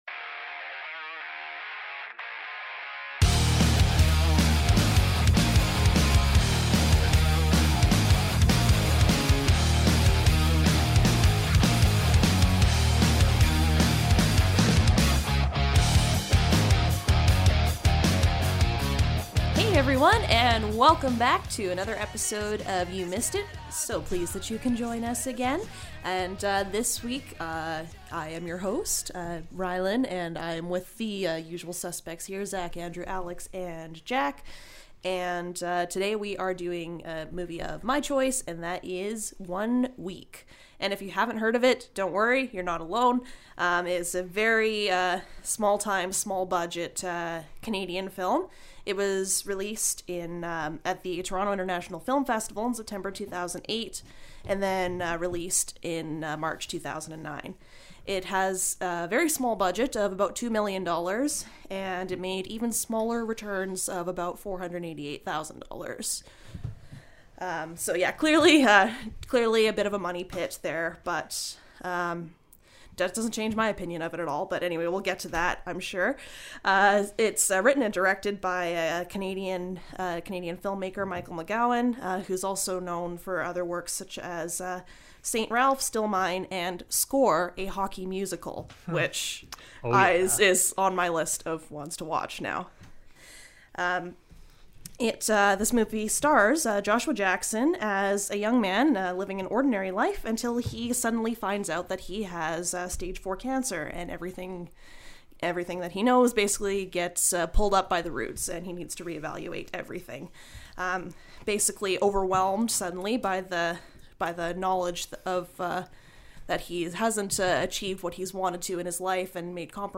Each episode, one of the hosts selects a movie to watch that they feel has not received the credit it deserves; afterwards everyone discusses and offers their opinions and their analyses to determine whether or not the movie is indeed underrated.